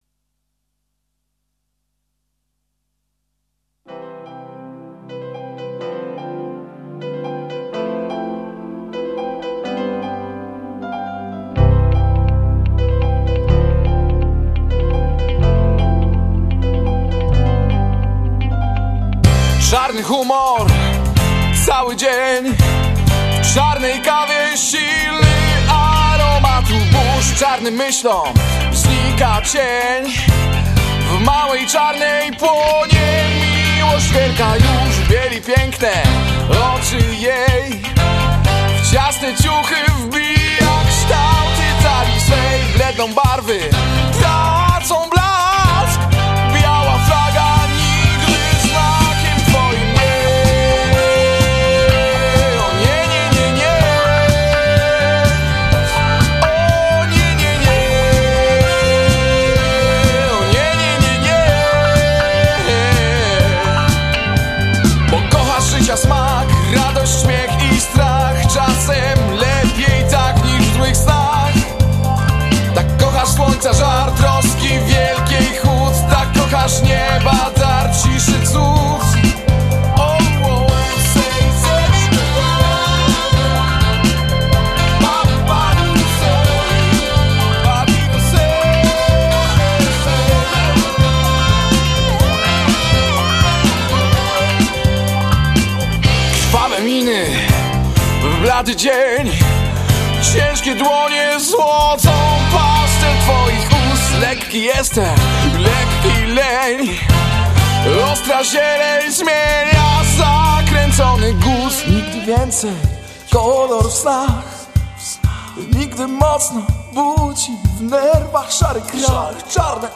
Gatunek: Rock, Reggae, Funk